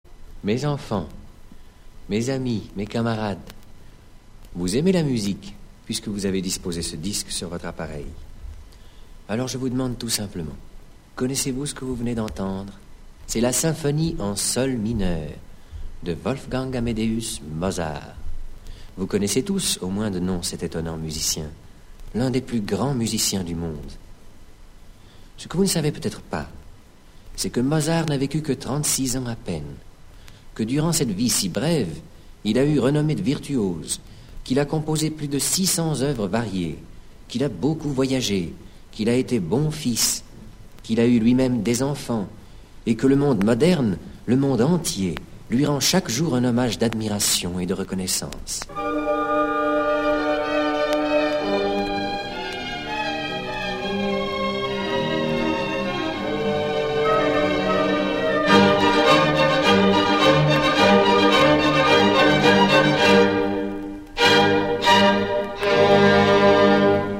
0% Extrait gratuit Mozart raconté aux enfants de Georges Duhamel Éditeur : Compagnie du Savoir Paru le : 2010 Le très talentueux comédien Gérard Philipe nous conte la vie fascinante et tragique de Wolfgang Amadeus Mozart. Le récit est agrémenté d'une dizaine d'extraits des oeuvres de Mozart. Comédiens : Gérard Philippe Auteur : Georges Duhamel Enregistrement original de 1955 Durée : 33 minutes Ch...